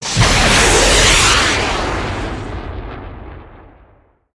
probelaunch.wav